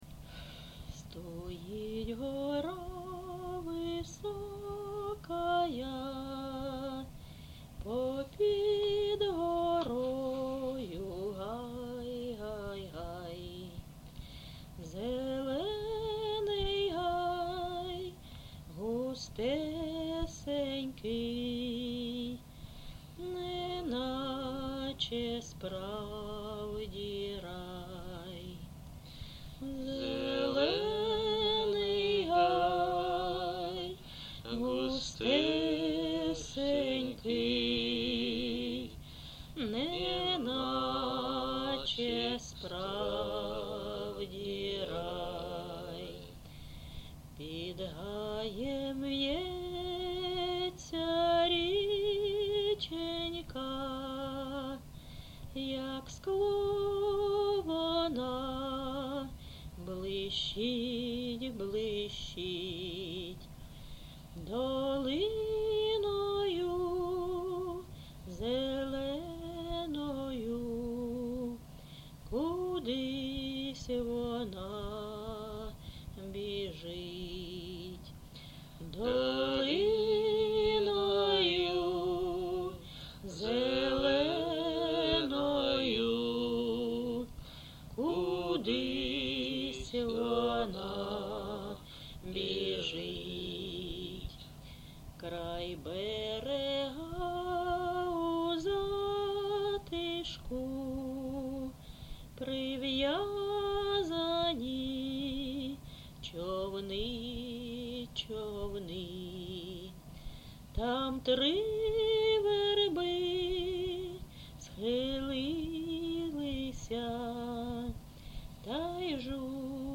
ЖанрПісні літературного походження
МотивЖурба, туга
Місце записум. Ровеньки, Ровеньківський район, Луганська обл., Україна, Слобожанщина